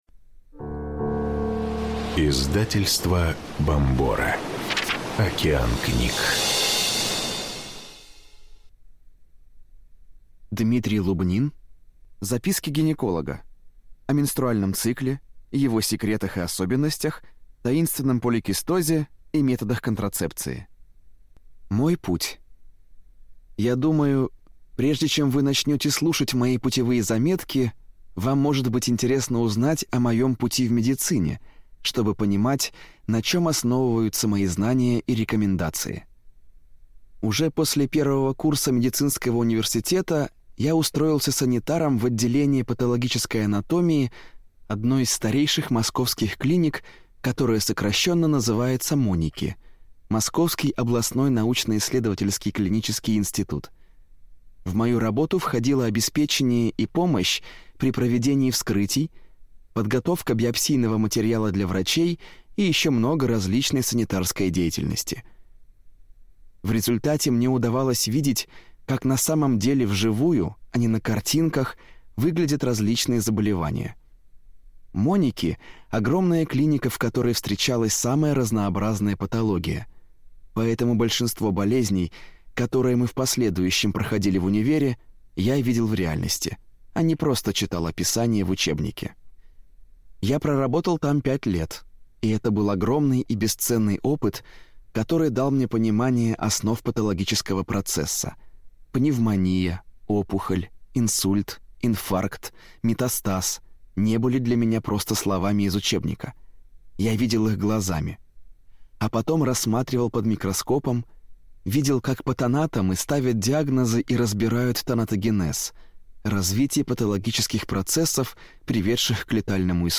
Аудиокнига Записки гинеколога. Сборник | Библиотека аудиокниг